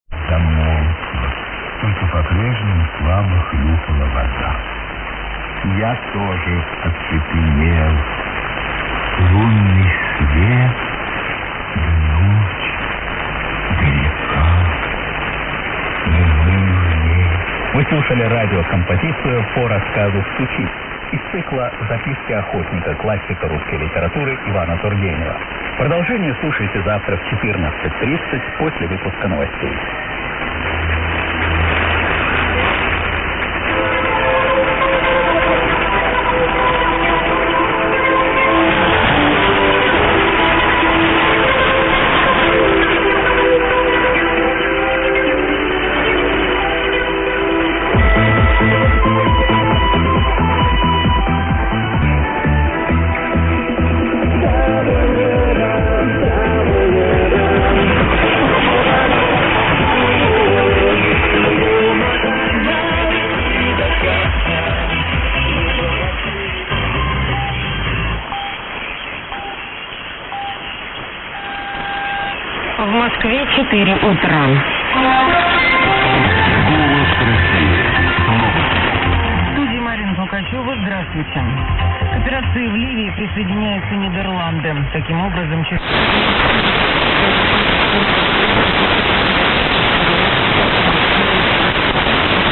It was heard again on the 1st April signing off at just after 0000 GMT.